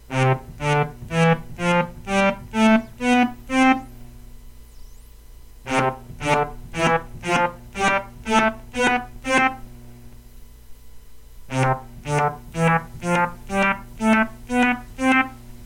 Sample 4: Same as #1, except that both the wave folder and VCA attacks have been set to 500ms, and both decays set to 0.